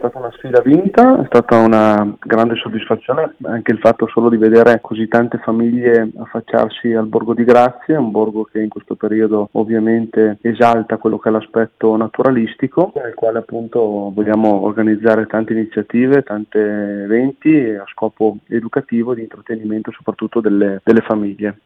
Una seconda edizione che avrà l’arduo compito di replicare il successo della scorsa, primissima volta per un evento che il vicesindaco Federico Longhi considera una scommessa vinta: